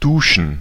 Ääntäminen
Ääntäminen France: IPA: [duʃ] Haettu sana löytyi näillä lähdekielillä: ranska Käännös Ääninäyte Verbit 1. duschen Muut/tuntemattomat 2.